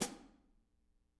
R_B Hi-Hat 04 - Close.wav